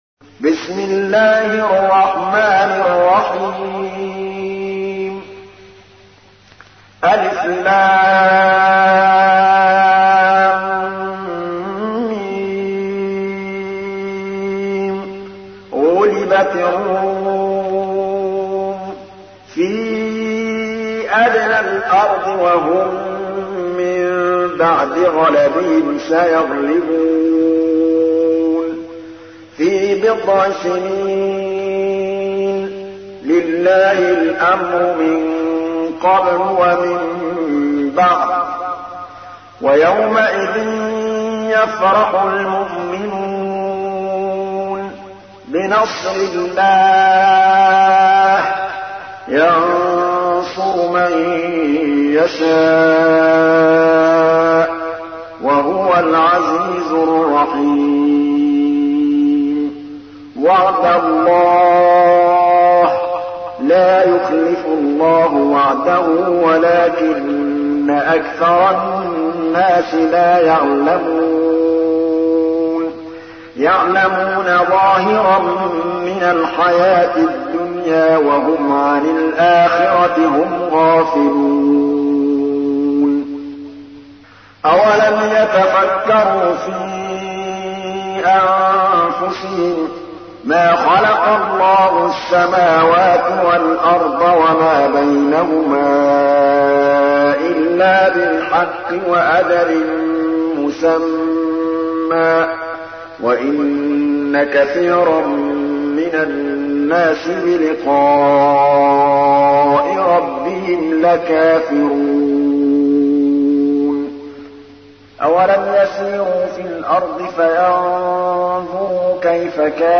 تحميل : 30. سورة الروم / القارئ محمود الطبلاوي / القرآن الكريم / موقع يا حسين